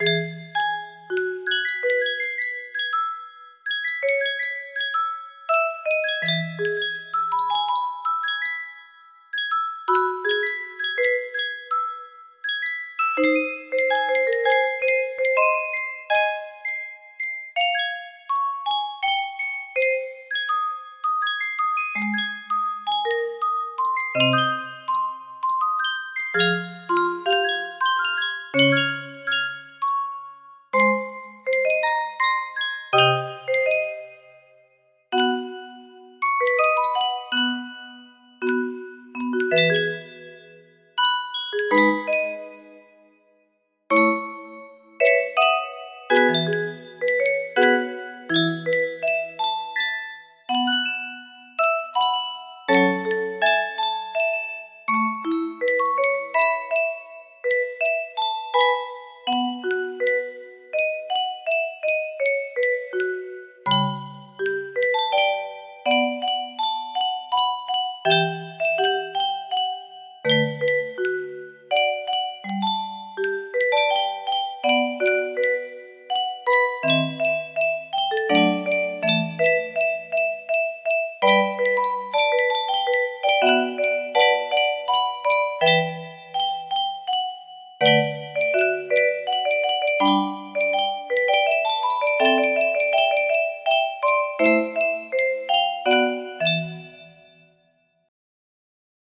The Call of Destiny C music box melody
Full range 60